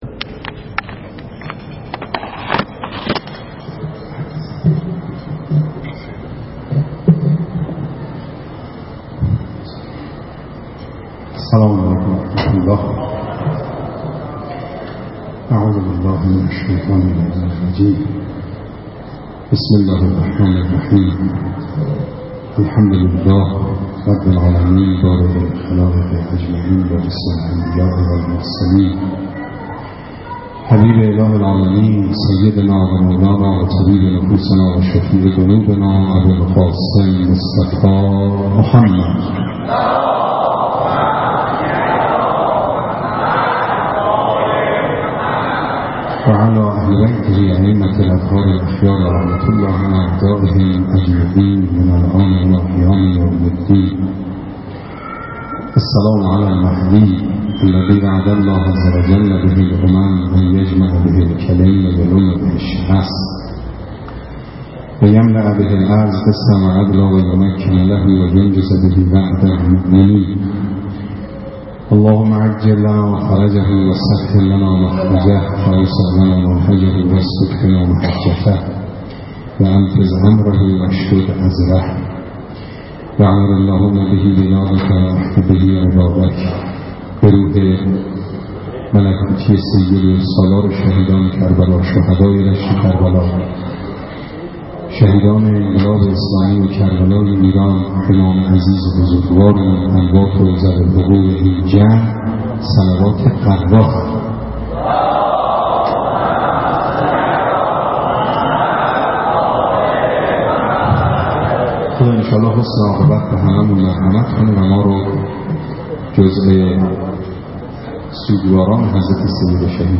مراسم عزاداری شب هفدهم محرم با حضور اقشار مختلف مردم
سخنرانی
در هیئت آل یاسین برگزار شد.
نوحه خوانی